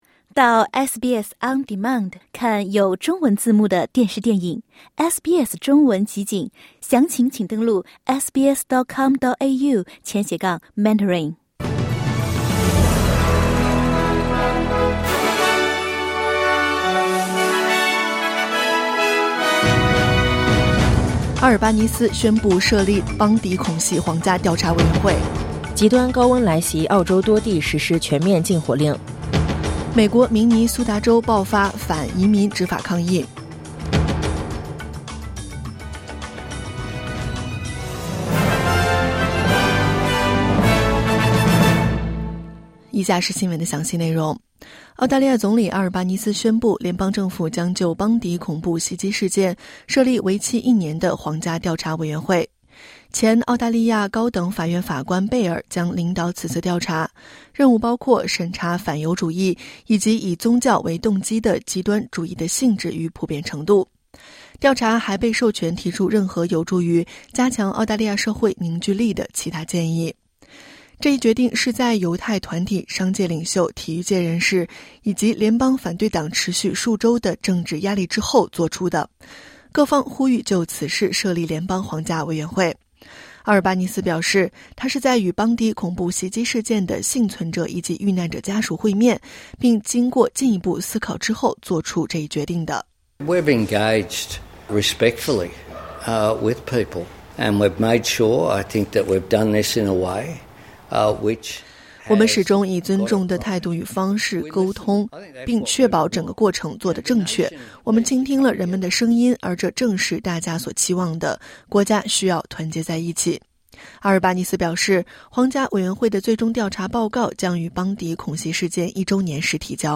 【SBS早新闻】澳洲多地实施全面禁火令